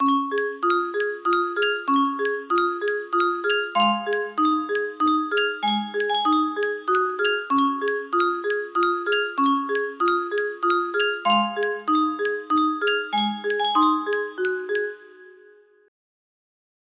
Yunsheng Music Box - Unknown Tune 1530 music box melody
Full range 60